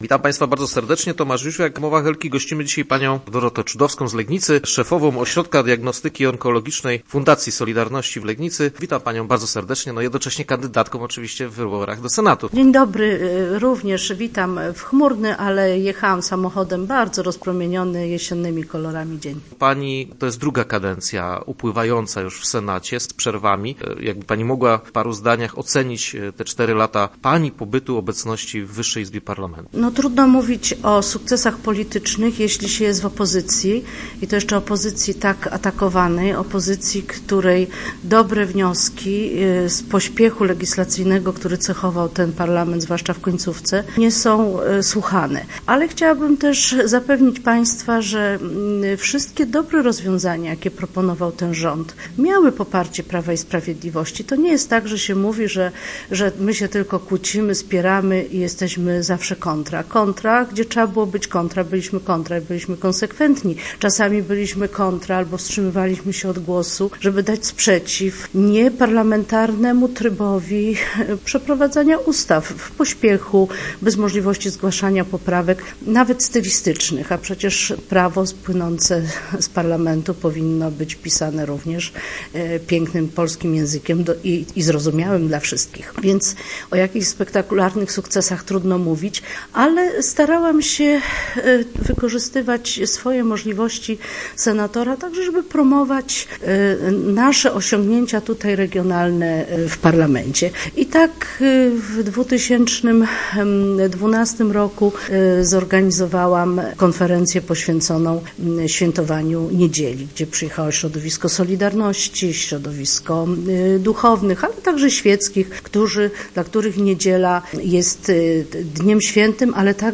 dorotaczudowska.jpgGościem piątkowych Rozmów była Dorota Czudowska, lekarz onkolog z Legnicy, która startuje w wyborach do Senatu z listy Prawa i Sprawiedliwości.